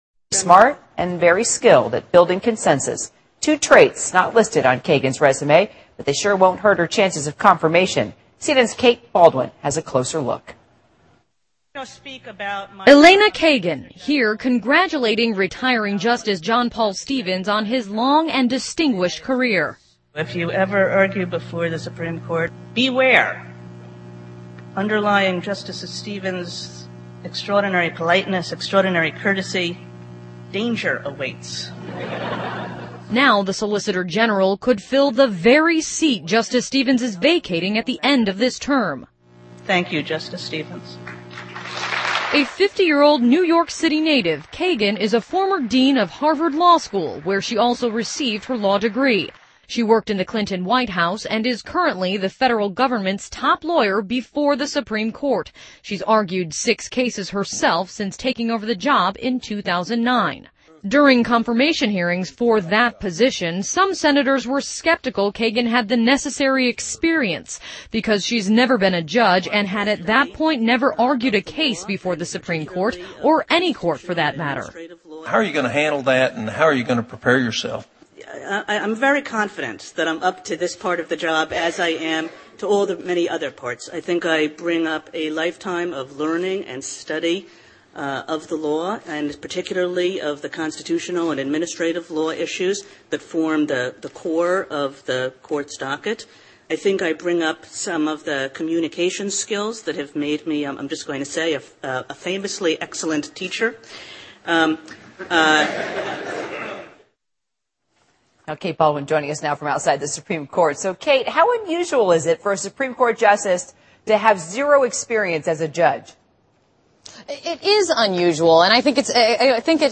CNN news:卡根被提名美大法官 各党派指责其无司法经验|CNN在线收听